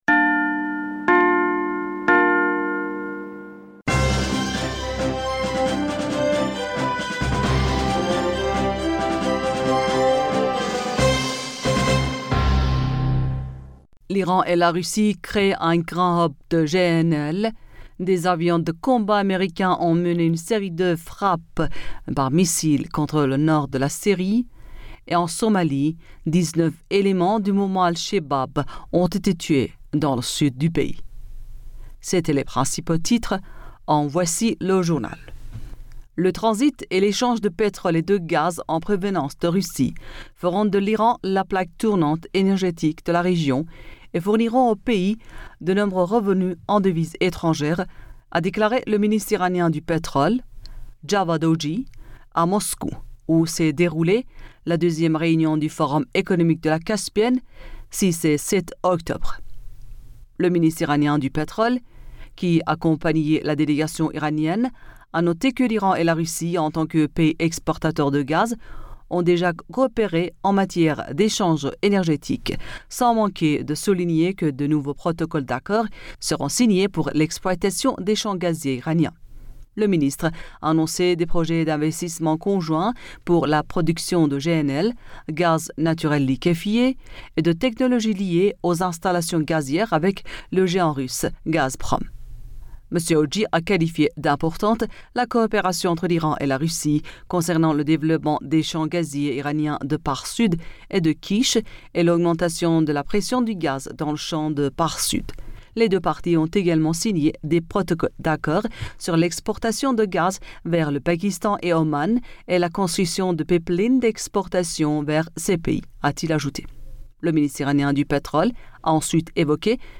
Bulletin d'information Du 08 Octobre